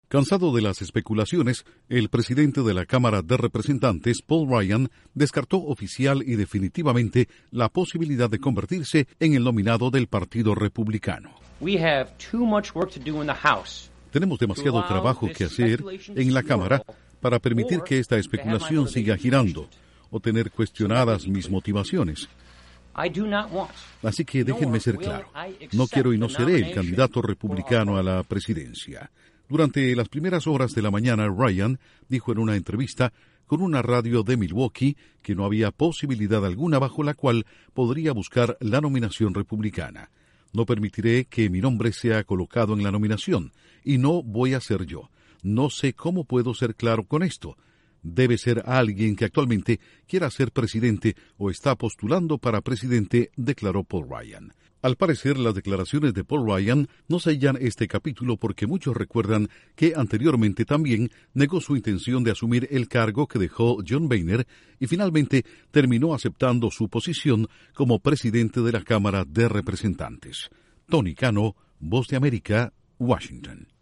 El presidente de la Cámara de Representantes de Estados Unidos, Paul Ryan, descarta la posibilidad de buscar la nominación republicana para las elecciones de noviembre. Informa desde la Voz de América en Washington